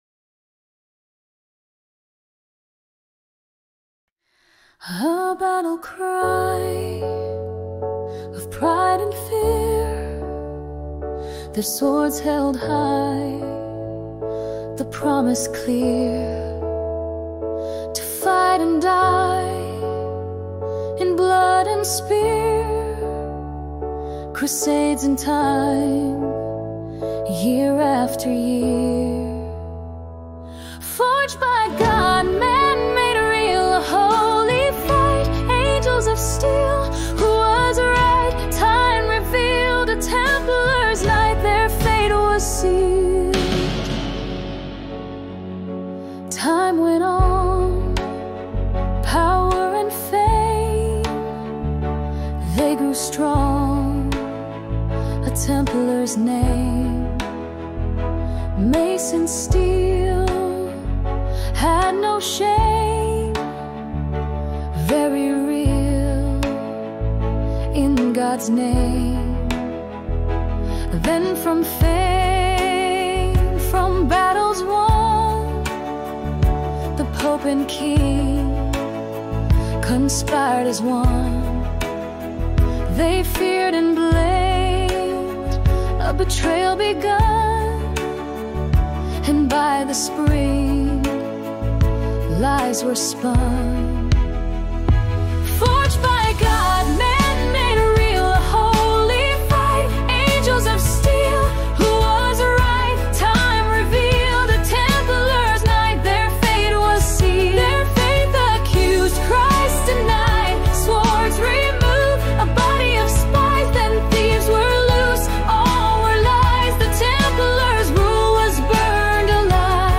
AI(Music)